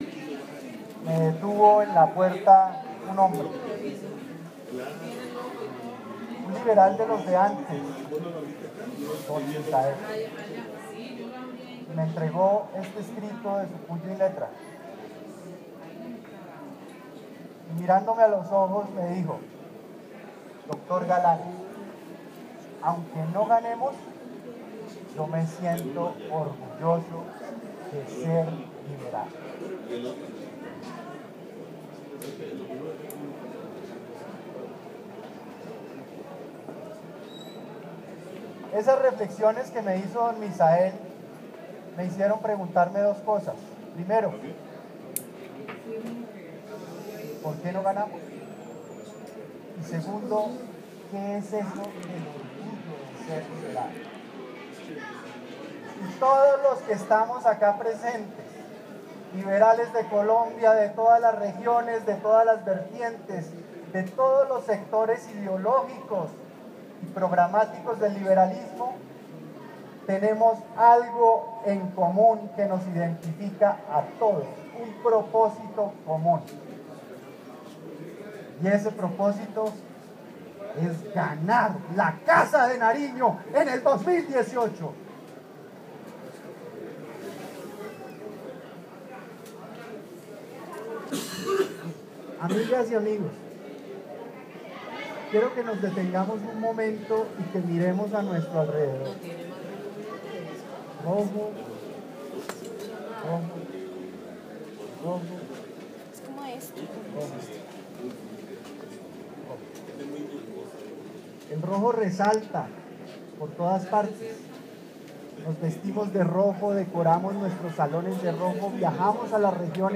Galán anuncia en Convención Liberal con emotivo e imponente discurso que será precandidato presidencial
En un discurso emotivo, brillante, lleno de pasión roja, Juan Manuel Galán reafirmó frente a miles de liberales y seguidores, su intención de ser el precandidato de la revolución liberal que necesita el Partido para enfrentar las elecciones del 2018.